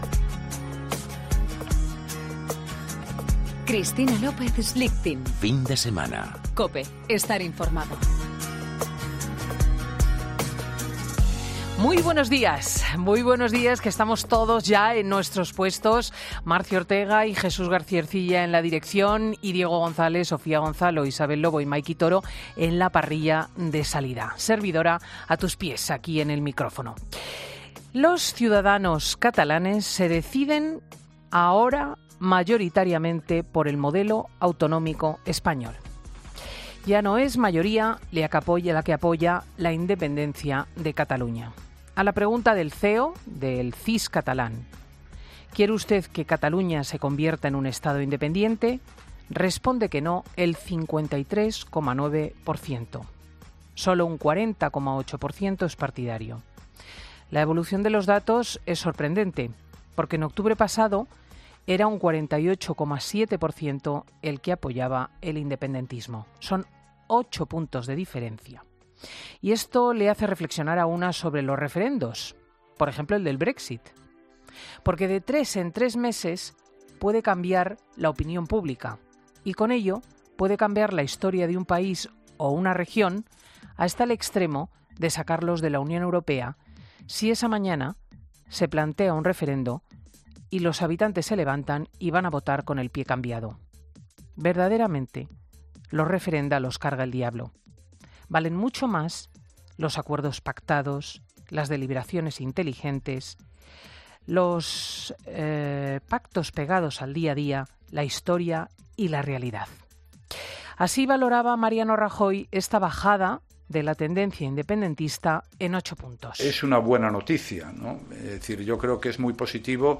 AUDIO: El CIS catalán, en el editorial de Cristina López Schlichting en 'Fin de Semana'